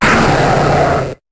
Cri de Granbull dans Pokémon HOME.